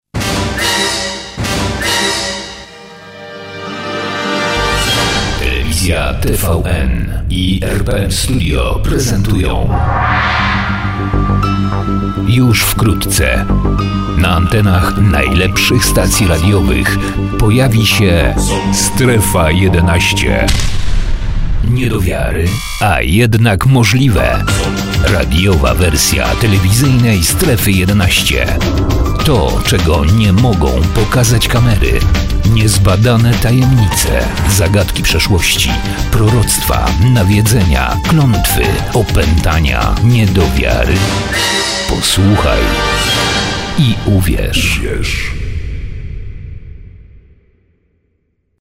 Professioneller polnischer Sprecher für TV / Rundfunk / Industrie.
Kein Dialekt
Sprechprobe: Industrie (Muttersprache):
polish voice over artist